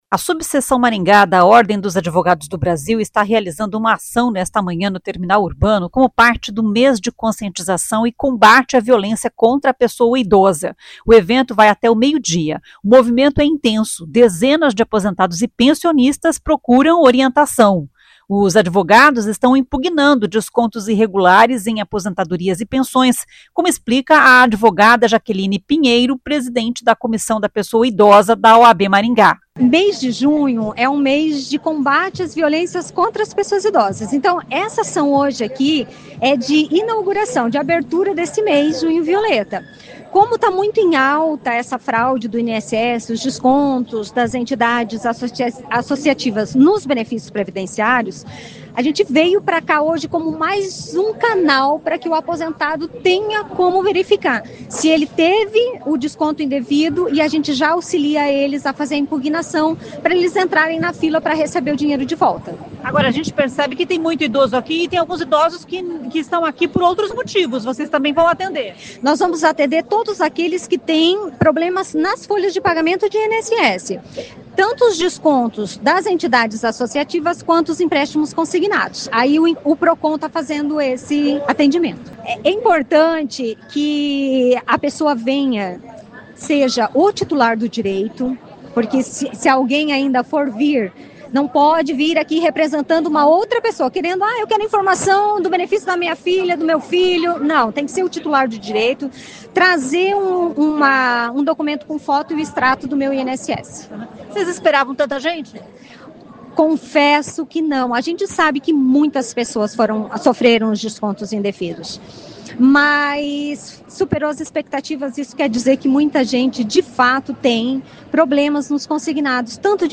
A subseção Maringá da Ordem dos Advogados do Brasil está realizando uma ação nesta manhã no Terminal Urbano, como parte do mês de conscientização e combate à violência contra a pessoa idosa.